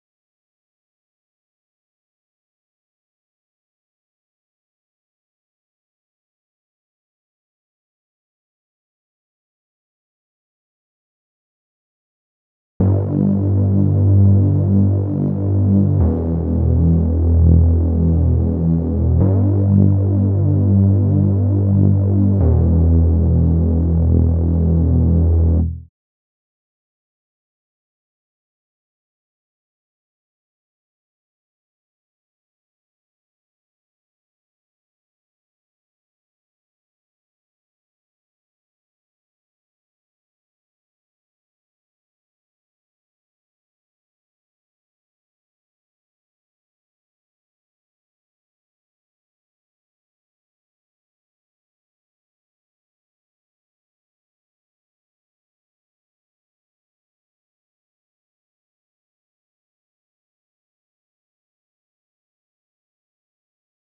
🔹 52 Premium Serum Presets built for synthwave, retro pop, and nostalgic melodic house.
Analog-Inspired Basses – Fat, gritty, and rich with retro character